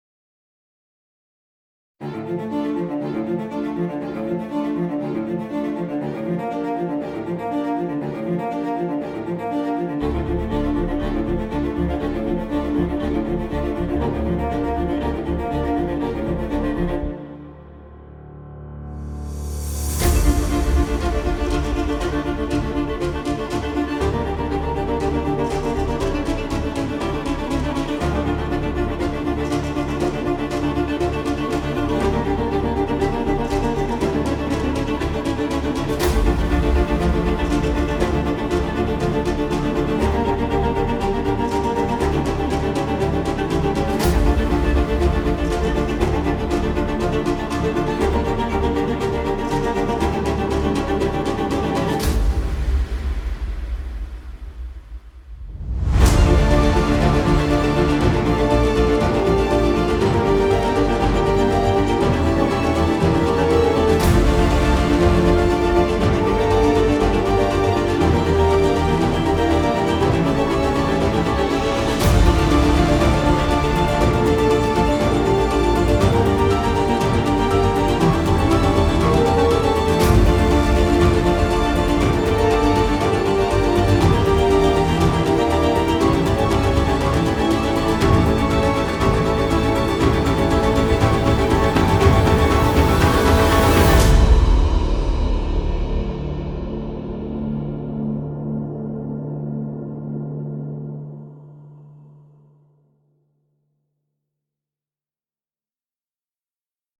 tema dizi müziği, duygusal heyecan aksiyon fon müziği.